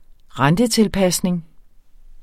Udtale [ ˈʁandəˌtelˌpasneŋ ]